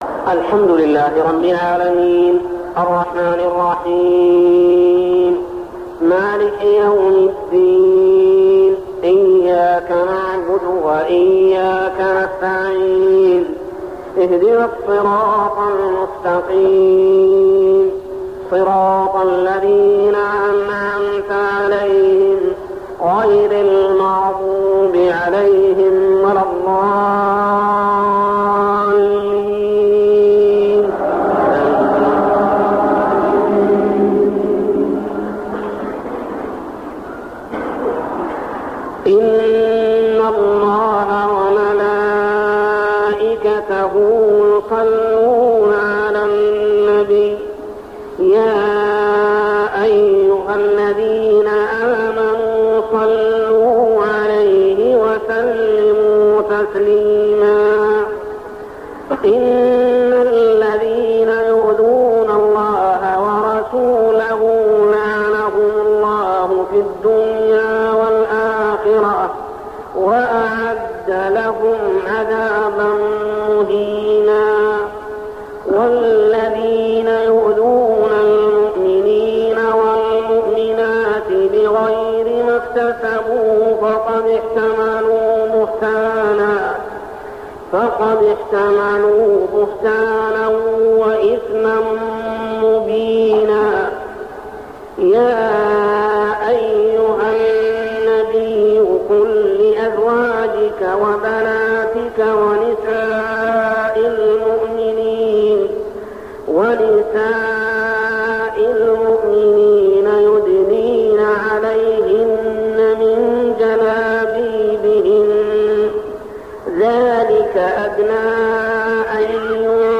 تلاوة من صلاة العشاء ليلة الختمة عام 1401هـ سورة الأحزاب 56-62 | Isha prayer Surah Al-Ahzab > 1401 🕋 > الفروض - تلاوات الحرمين